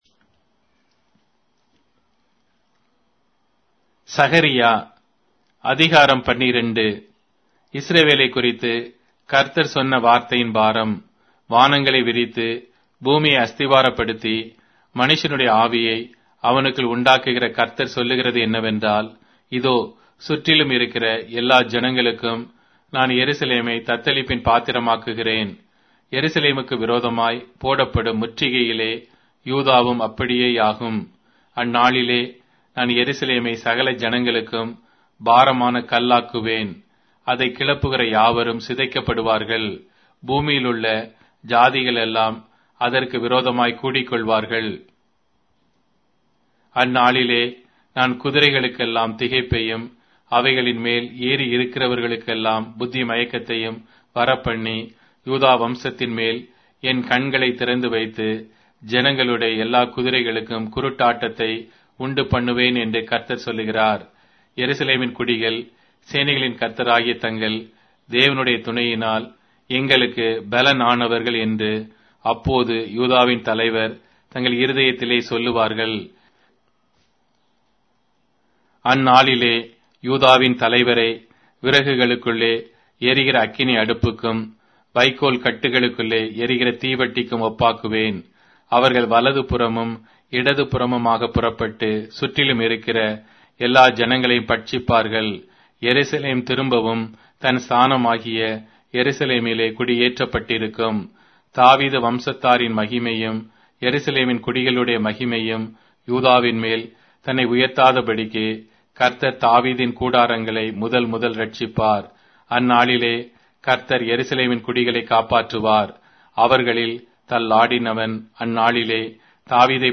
Tamil Audio Bible - Zechariah 4 in Tev bible version